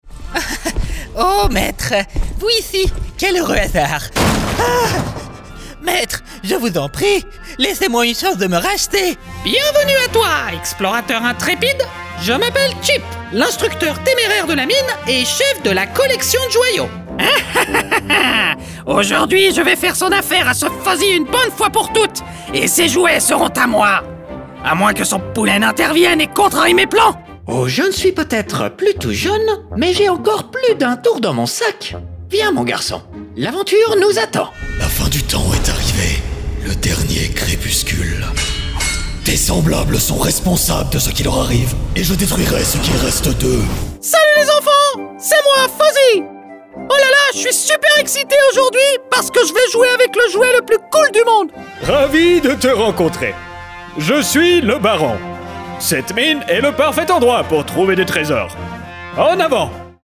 Voix off
Compilation personnages réalistes (EN)
Mon timbre naturel est medium-grave, chaleureux et amical, mais aussi professionnel et fiable.
Je travaille depuis mon Home Studio, à Kyoto au Japon, avec des clients partout dans le monde.